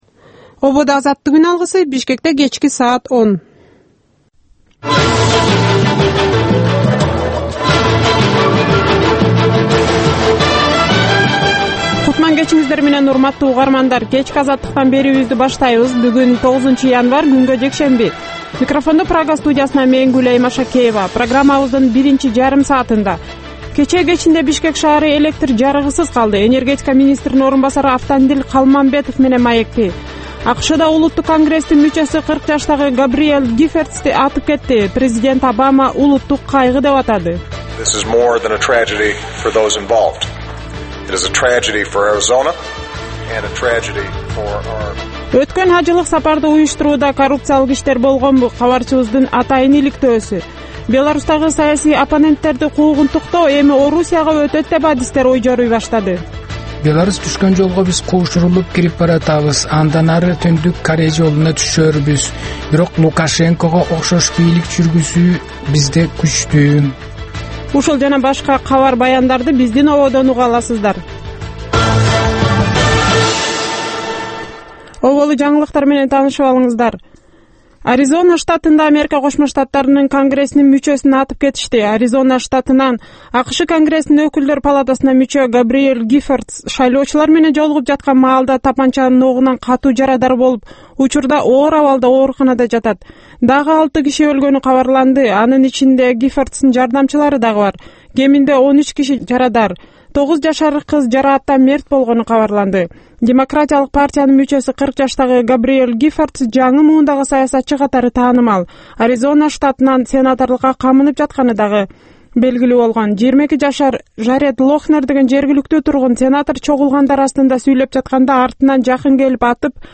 Кечки 10догу кабарлар